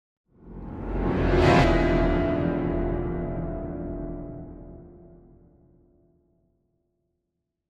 Нагоняющие страх звуки для монтажа видео и просто испугаться слушать онлайн и скачать бесплатно.
7. Резкий звук, который должен нагнать страху